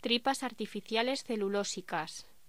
Locución: Tripas artificiales celulósicas
voz